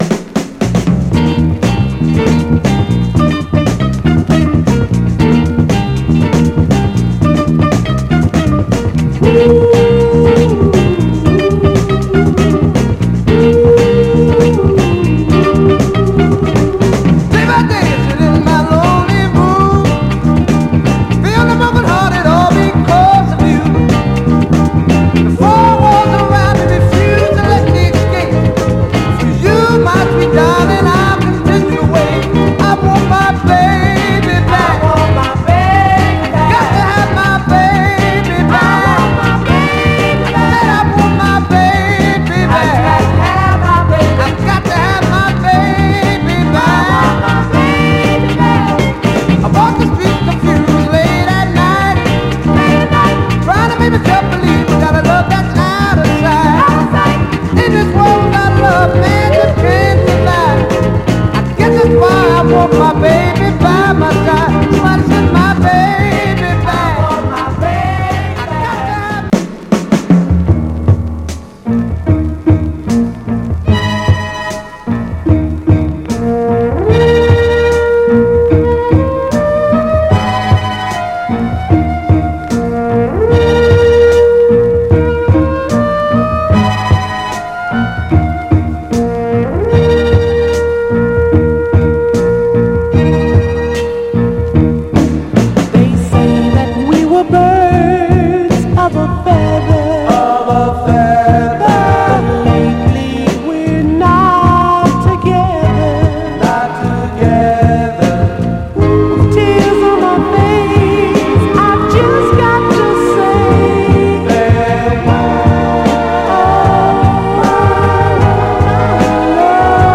疾走感と高揚感がフロアで大活躍するクロスオーヴァー・ソウル/ノーザン・ダンサー
※試聴音源は実際にお送りする商品から録音したものです※